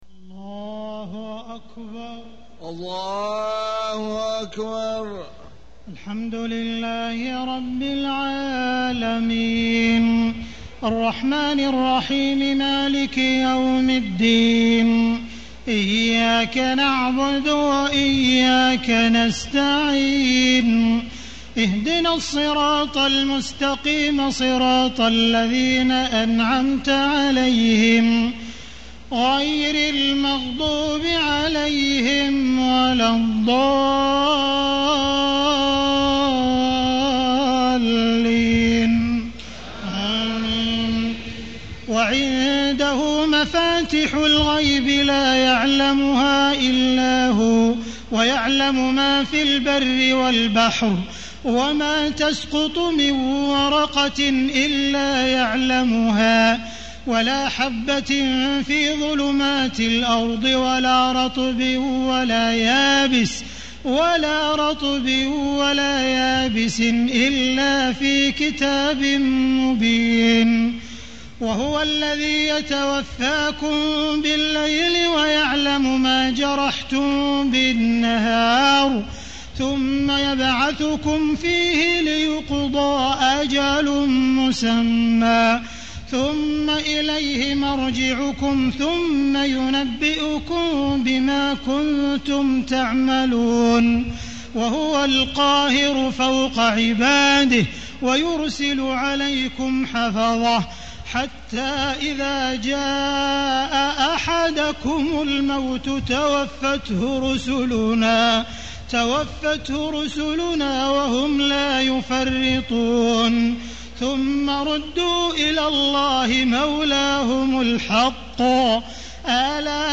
تهجد ليلة 27 رمضان 1435هـ من سورة الأنعام (59-111) Tahajjud 27 st night Ramadan 1435H from Surah Al-An’aam > تراويح الحرم المكي عام 1435 🕋 > التراويح - تلاوات الحرمين